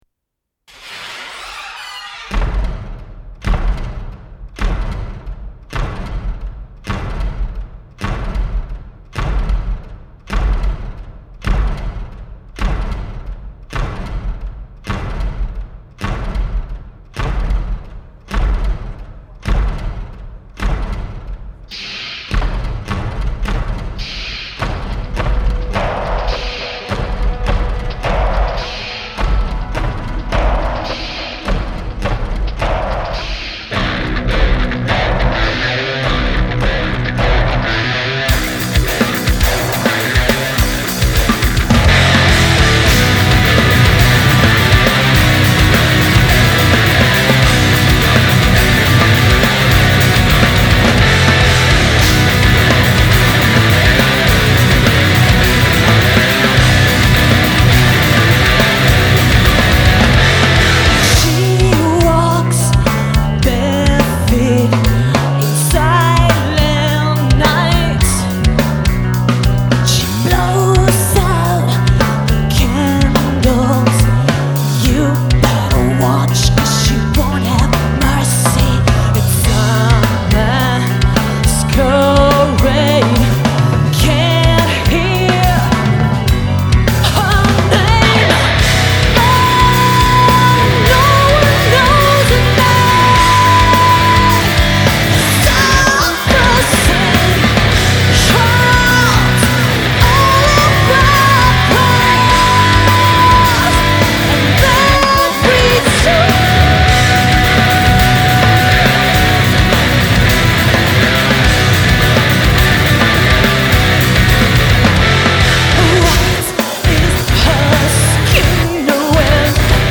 Vocals
Lead Guitars
Drums
Machines